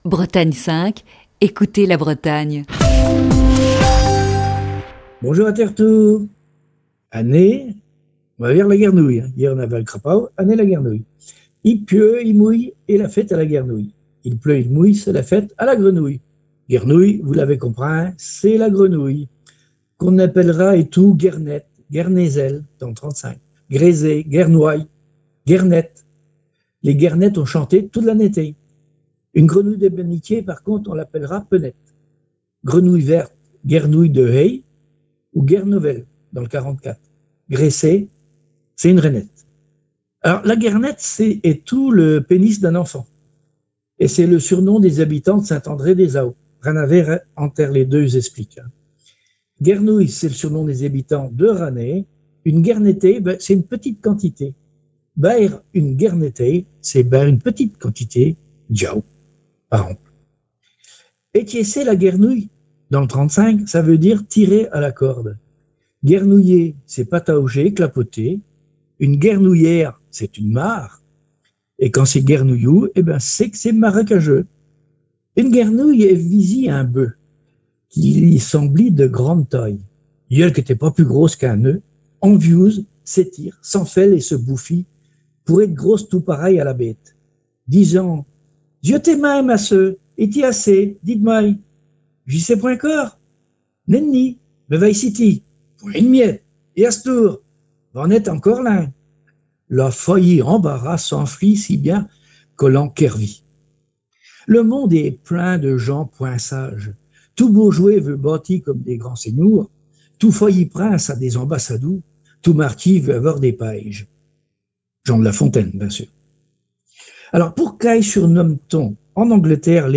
Chronique du 7 janvier 2021.